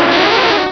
Cri de Kokiyas dans Pokémon Rubis et Saphir.